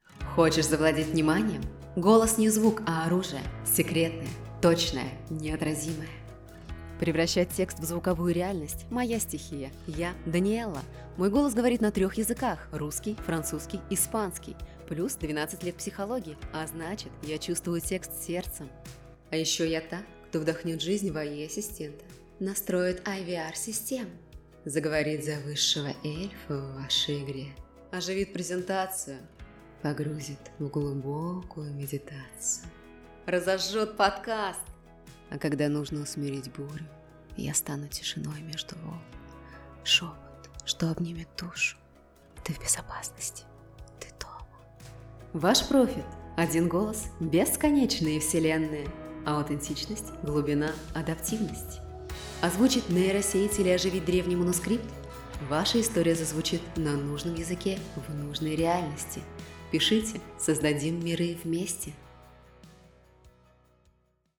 Пример звучания голоса
Жен, Рекламный ролик/Молодой
Записываю в изолированной комнате. Микрофон Recording tools MCU-02, окруженный акустическими щитами.